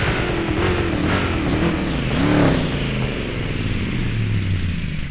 Index of /server/sound/vehicles/lwcars/buggy
slowdown_highspeed.wav